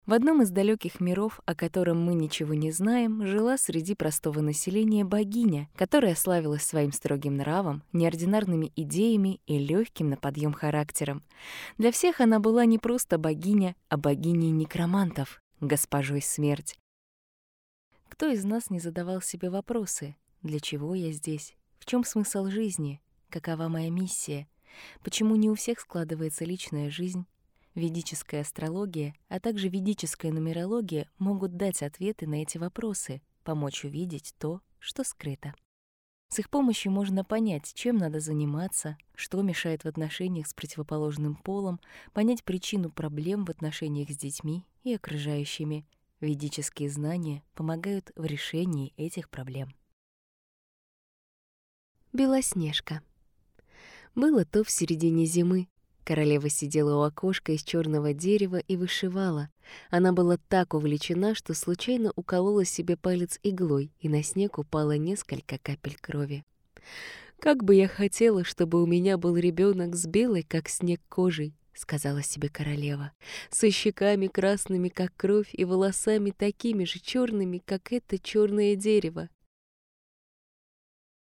Работаю в различных жанрах - от медитаций до рекламы!
Тракт: Микрофон ARK FET, звуковая карта Audient ID4 MKII, Дикторская кабина